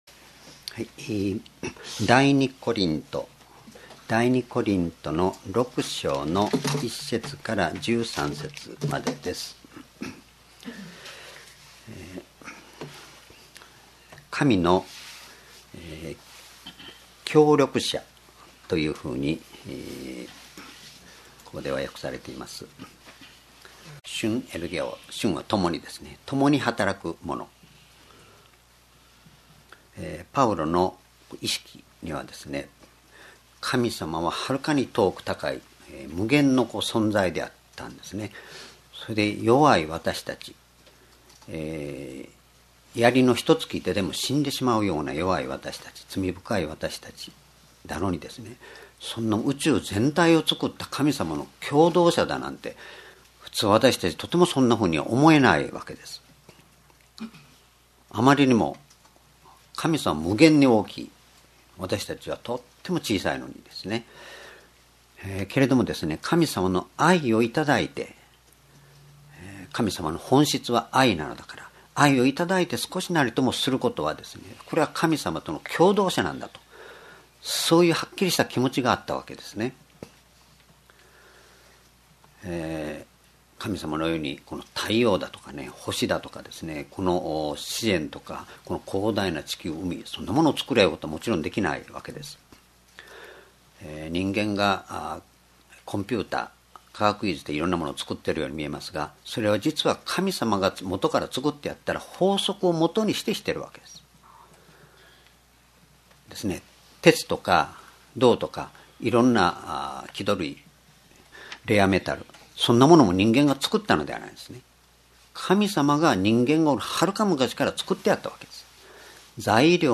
主日礼拝日時 ２０１４年３月９日 聖書講話箇所 コリントの信徒への手紙二 6章1-13 「主によってすべてを持つ」 ※視聴できない場合は をクリックしてください。